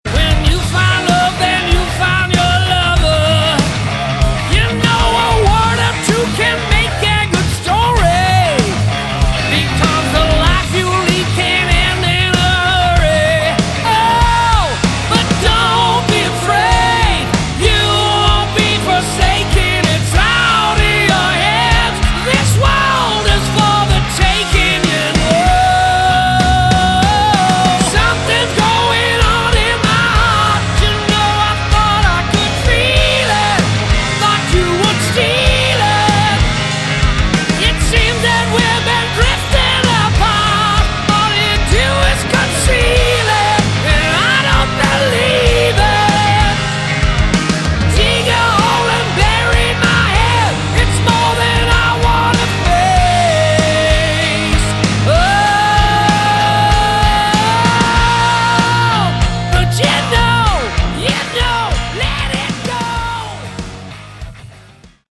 Category: Hard Rock
bass, additional guitars
drums
keyboards